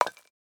stonesuccess1.wav